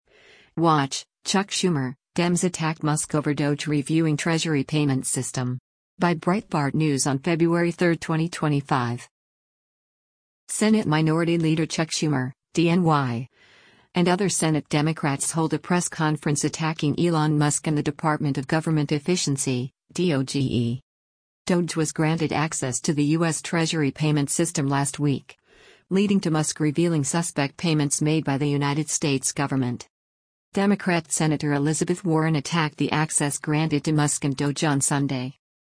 Senate Minority Leader Chuck Schumer (D-NY) and other Senate Democrats hold a press conference attacking Elon Musk and the Department of Government Efficiency (DOGE).